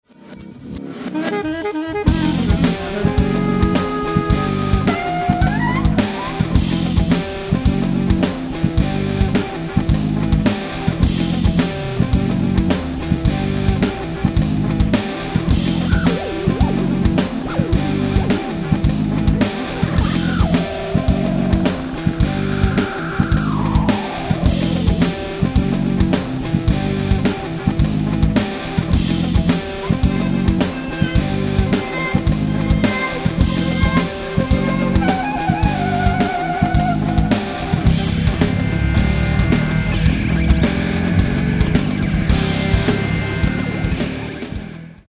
electric bass, keyboards & programming on track 6
guitar on tracks 1, 4, 5 & 8
drums on tracks 1, 4, 5 & 8
alto saxophone on tracks 1, 4, 5 & 8